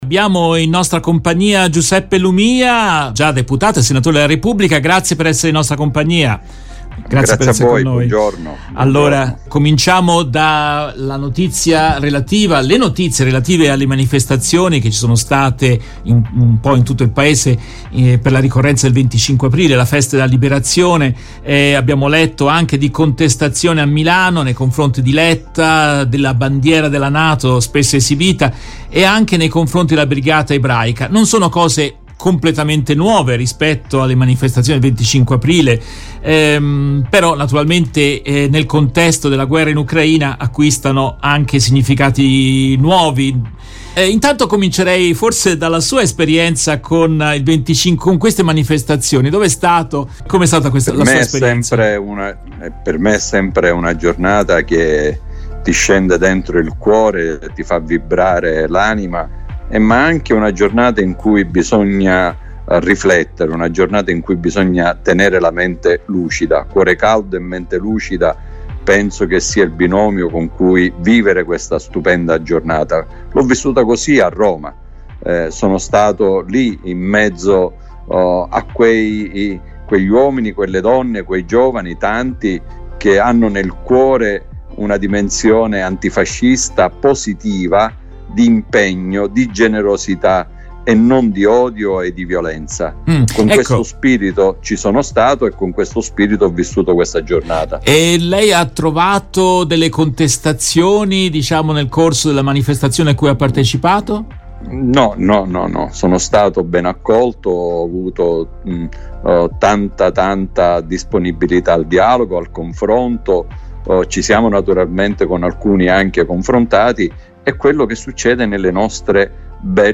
In questa intervista tratta dalla diretta RVS del 26 aprile 2022